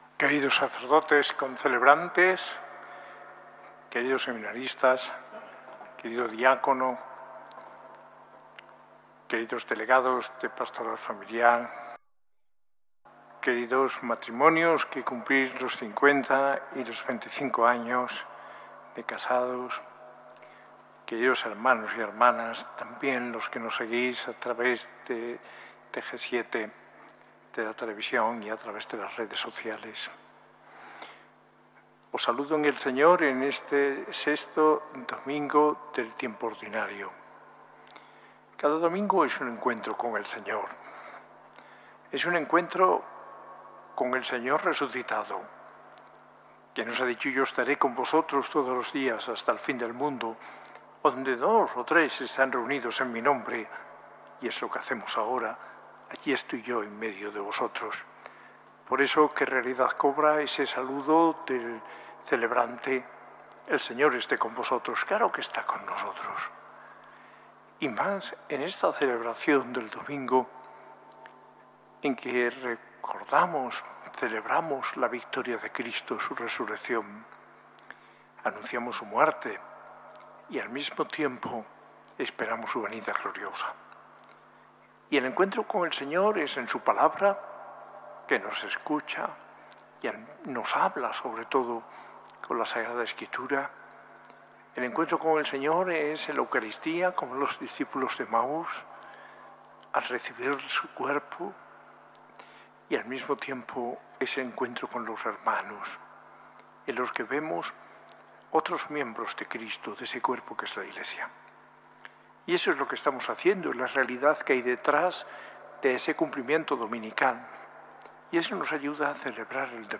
Homilía de D. José María Gil Tamayo, arzobispo de Granada, en la Eucaristía del VI Domingo del T.O y renovación de las promesas matrimoniales de los cónyuges que celebran su 50 y 25 aniversario de sacramento esponsal, celebrada en la Catedral el 15 de febrero de 2026.